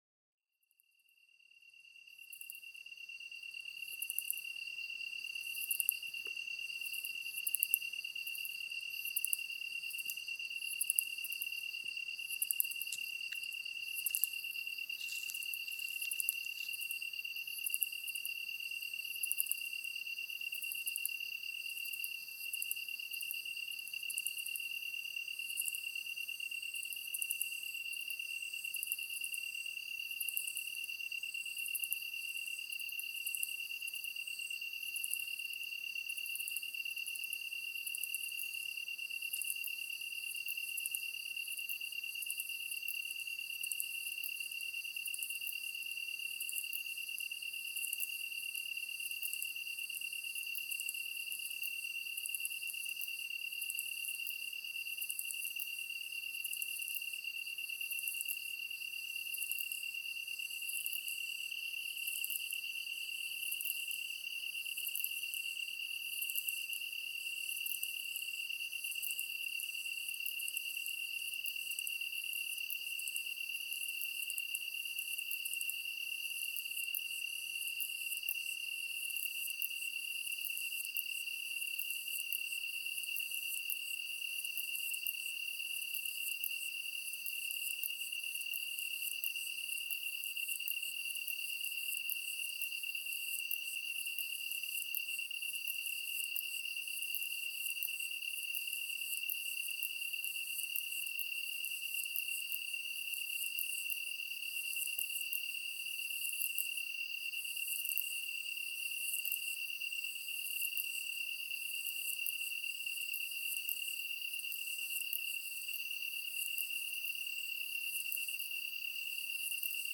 カンタン　Oecanthus longicaudaコオロギ科
日光市稲荷川中流　alt=740m
MPEG Audio Layer3 FILE 128K 　3'37''Rec: SONY PCM-D50
Mic: Panasonic WM-61A  Binaural Souce with Dummy Head
他の自然音：エゾツユムシ、ツヅレサセコオロギ、ヤマヤブキリ